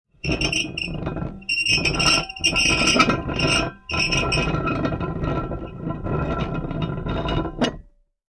Металлическое колесо движется по рельсам